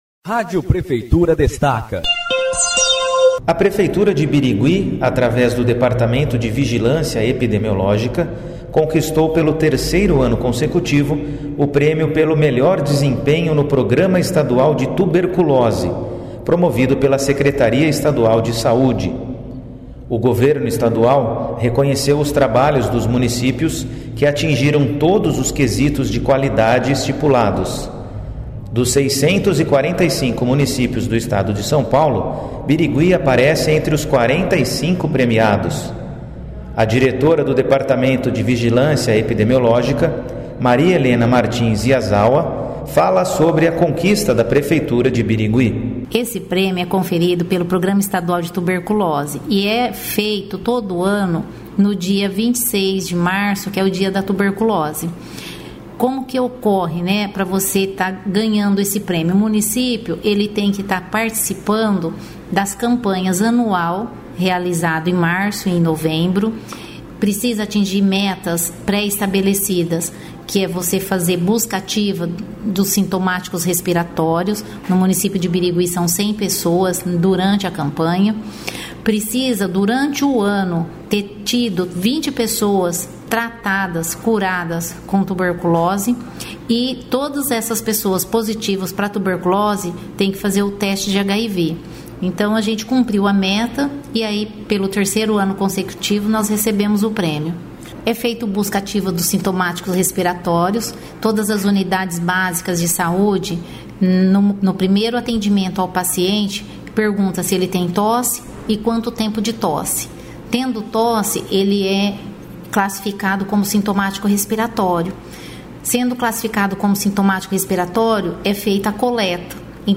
Sonora: